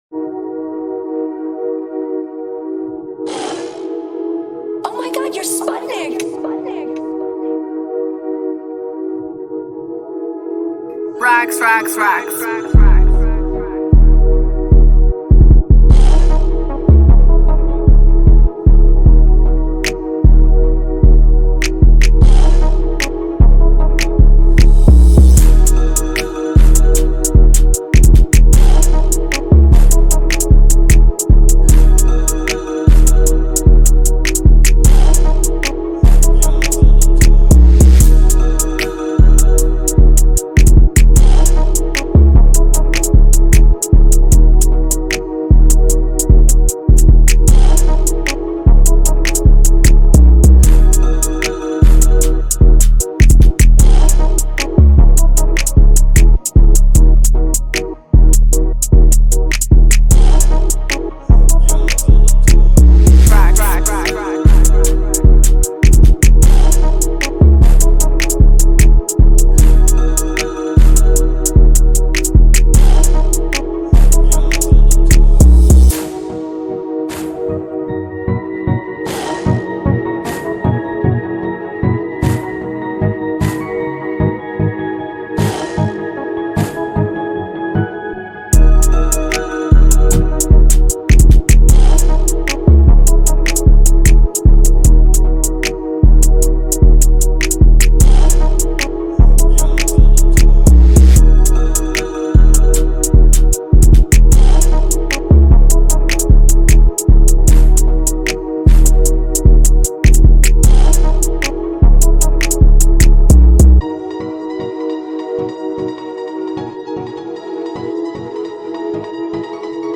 2024 in NY Drill Instrumentals